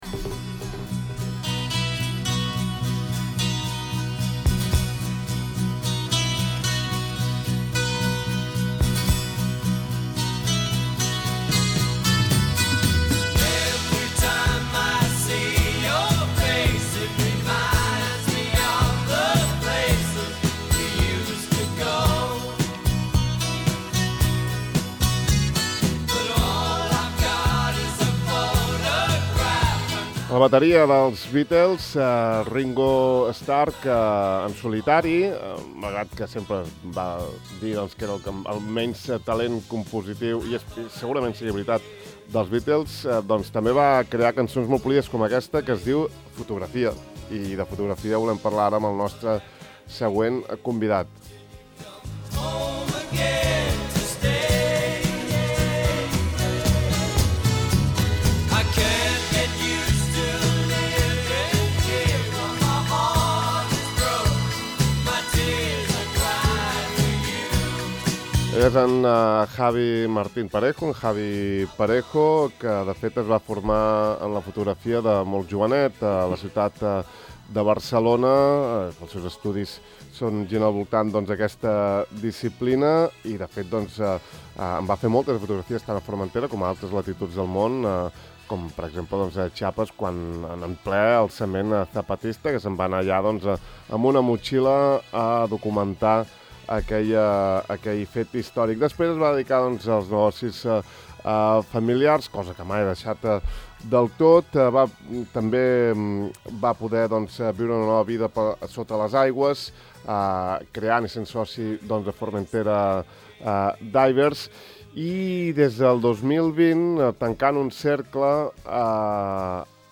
Sobre l’enfocament que ell fa en la seva tasca de narrar una realitat a través de les imatges, sigui en la logística o en els aspectes ètics, n’hem parlat aquest matí al De far a far en una llarga i interessant entrevista.